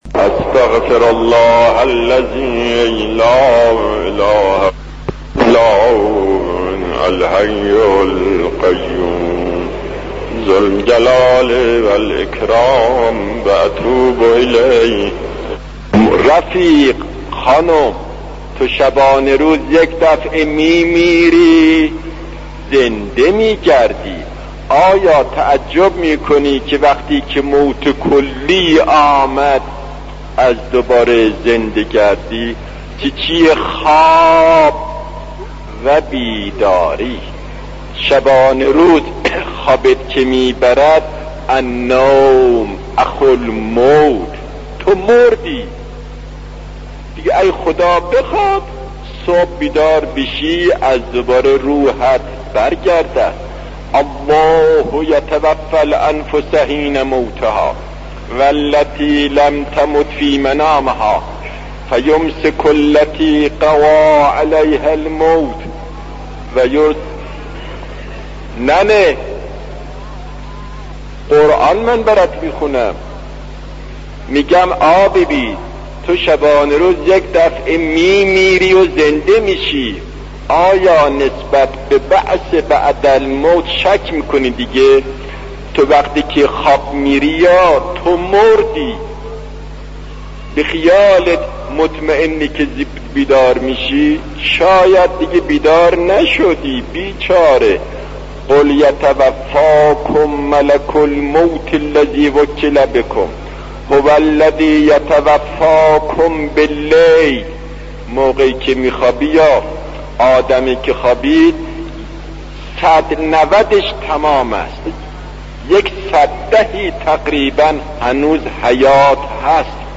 سخنرانی تامل برانگیز شهید آیت الله دستغیب راجع به انس با پروردگار و مرگ را در این صوت بشنوید.